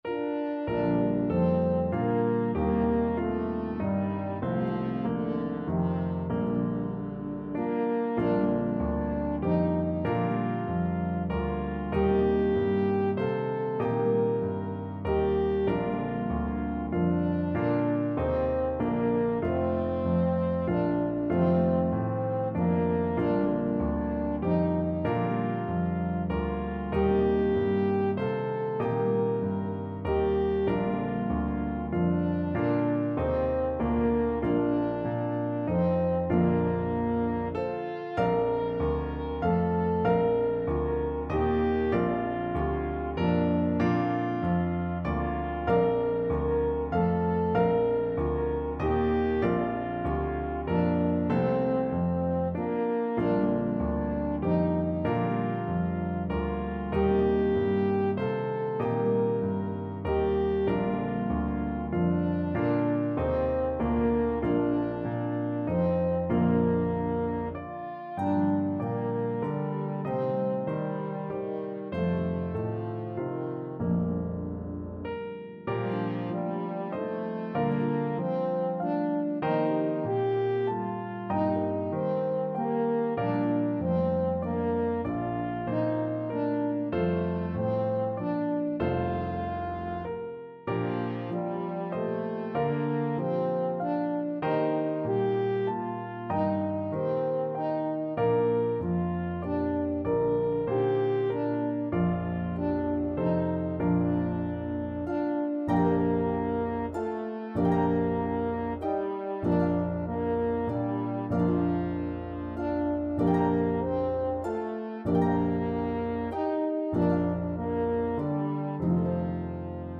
Harp, Piano, and French Horn version